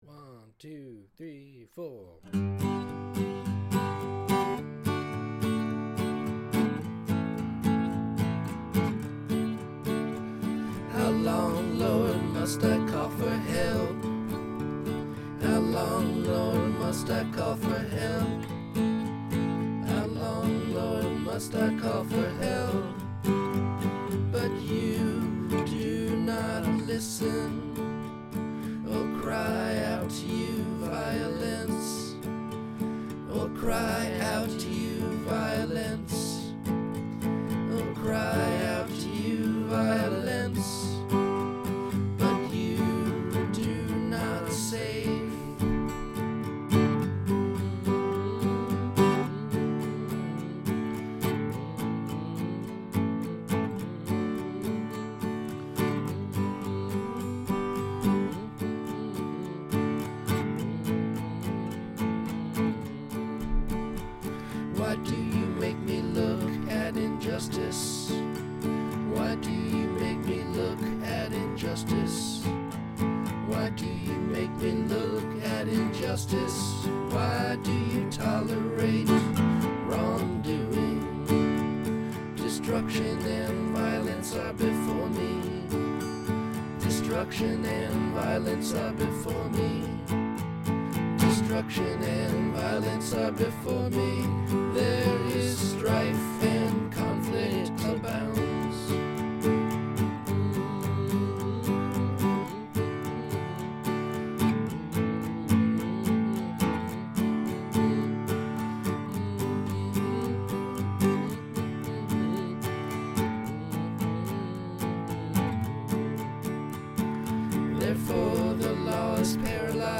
demo recording